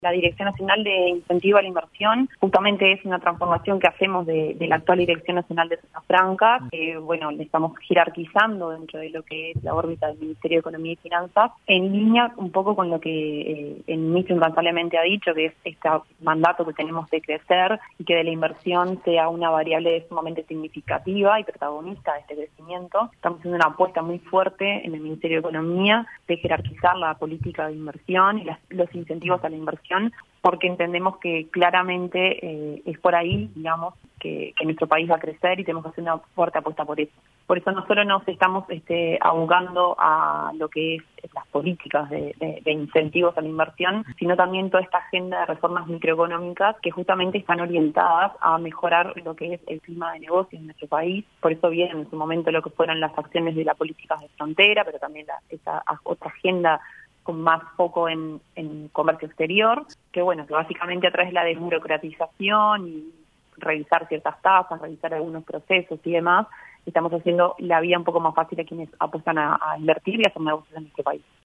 La Directora Nacional de Zonas Francas, Isabella Antonaccio en entrevista con 970 Noticias explicó en que consistirá la creación de la Dirección Nacional de Incentivo a la Inversión.